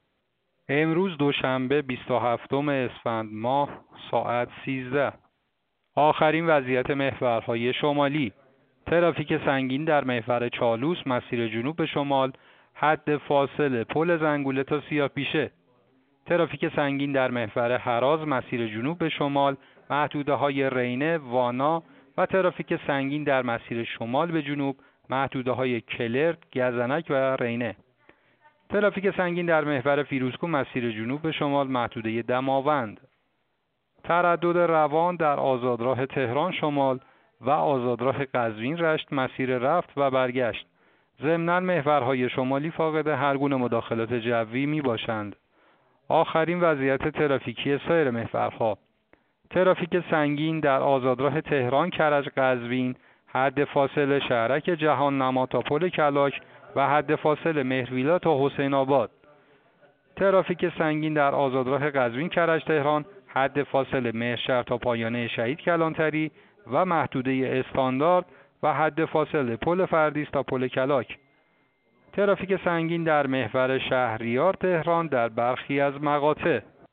گزارش رادیو اینترنتی از آخرین وضعیت ترافیکی جاده‌ها ساعت ۱۳ بیست و هفتم اسفند؛